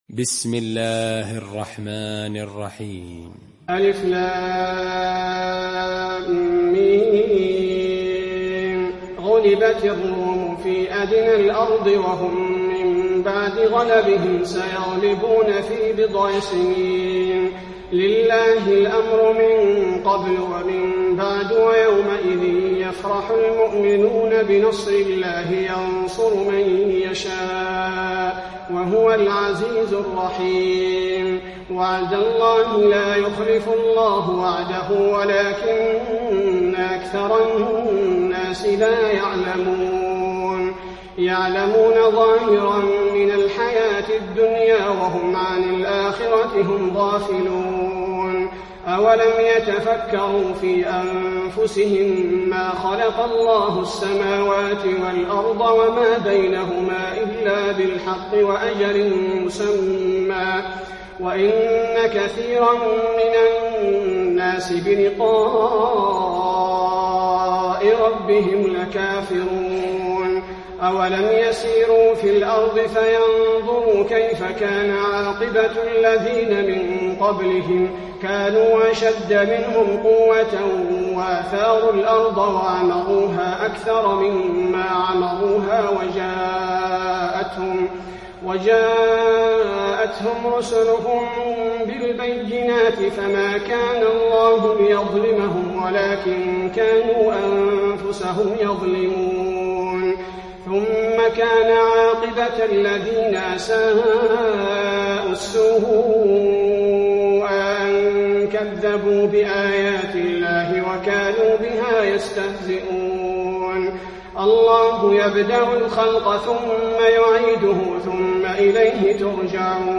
المكان: المسجد النبوي الروم